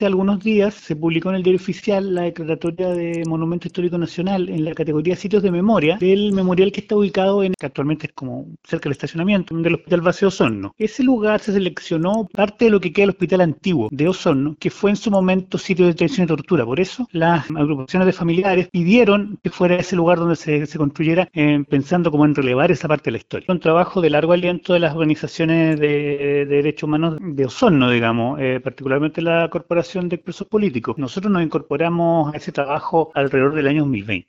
En conversación con Radio Bío Bío